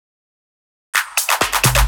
Fill 128 BPM (25).wav